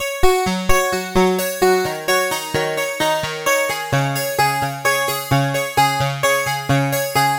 Tag: 130 bpm Electronic Loops Synth Loops 1.24 MB wav Key : Unknown